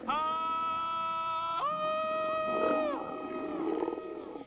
At 5:57 in the episode, Annie swings in on a vine (like Christa) and seems to be mimicking Christa's jungle yell.
howl.wav